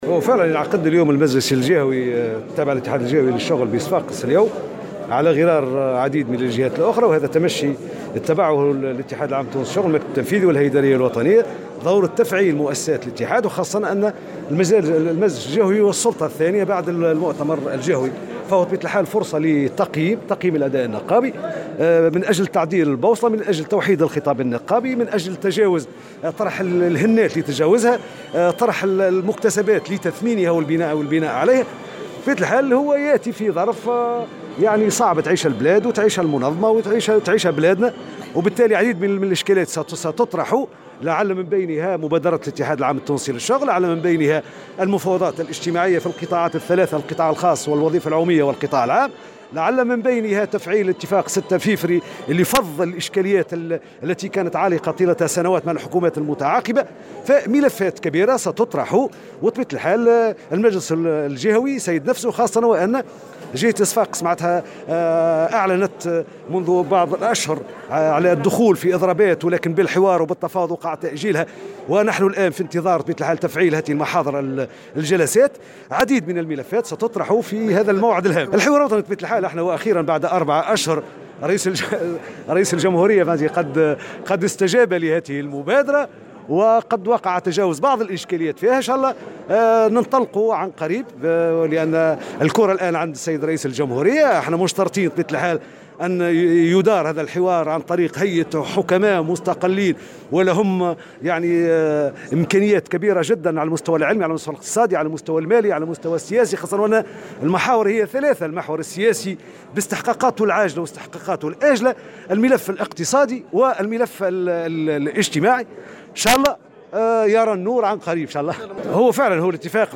وقال حفيظ في تصريح لمراسل الجوهرة اف أم، في افتتاح المجلس الجهوي لاتحاد الشغل بصفاقس، إن الاتحاد يشترط أن يُدار هذا الحوار من قبل هيئة حكماء مستقلين من ذوي الكفاءة العلمية والسياسية والاقتصادية، معبرا عن أمله في الانطلاق قريبا في هذا الحوار الذي سيتناول الأزمة التي تمر بها البلاد اقتصاديا وسياسيا واجتماعيا.